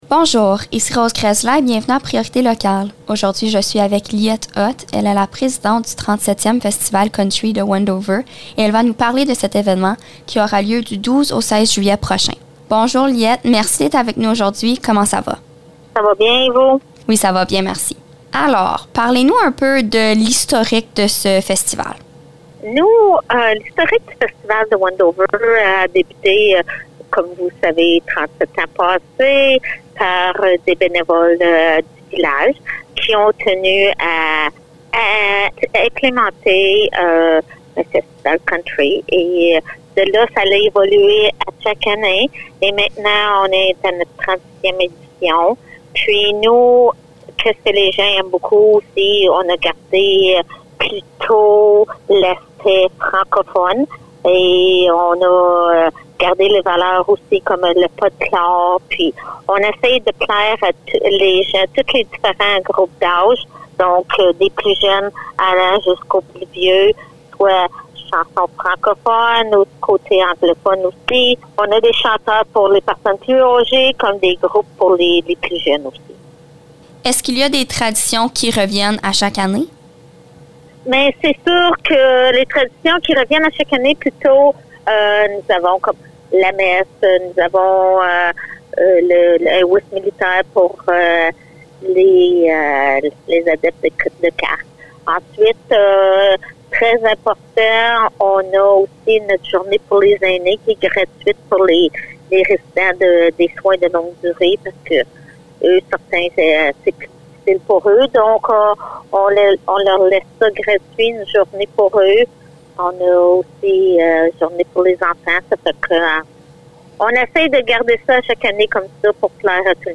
Entrevue-Wendover.mp3